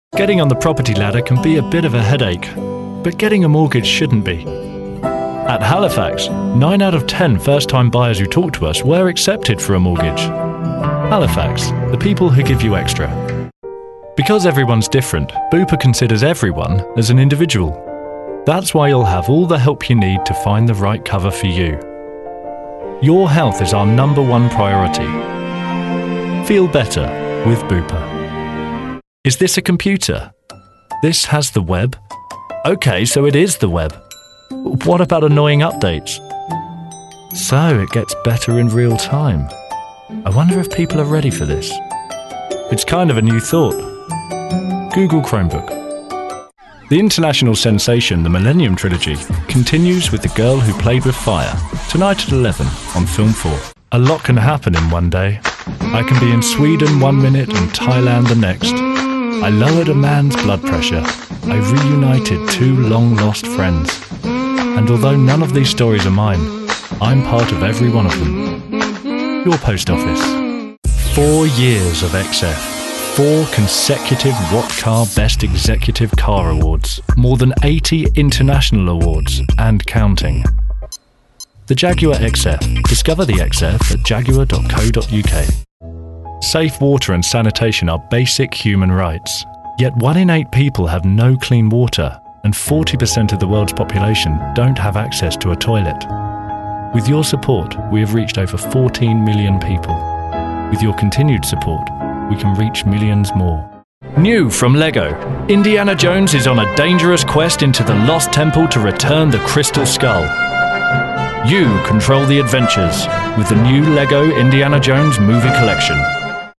Warm, natural feel, authoritative but relatable tone and clean delivery.
britisch
Sprechprobe: Industrie (Muttersprache):